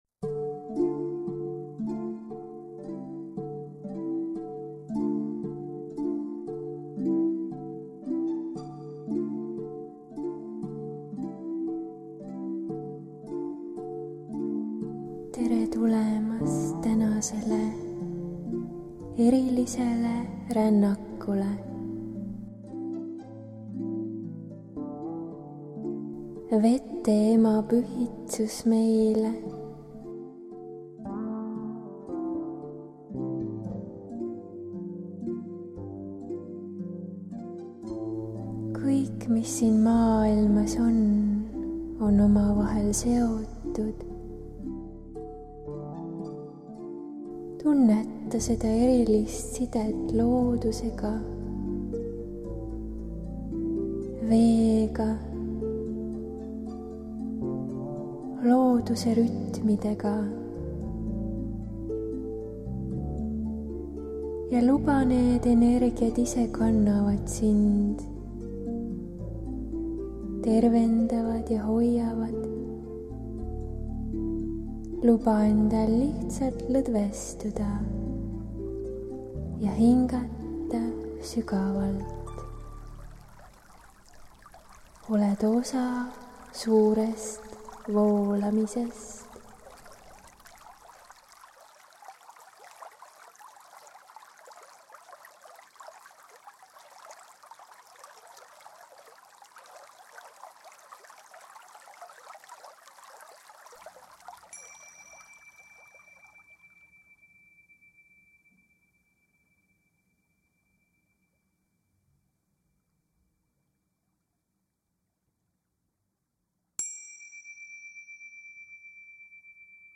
See meditatsioon on Vete-Ema sõnum meile läbi energeetilise edastuse, helide ja sõnumite. Seekordne meditatsioon ongi väga eriline just selle poolest, et nagu sa ka ise kuulma hakkad, on selels palju enam muusikat ja helivärve kui minu varasemates edastustes.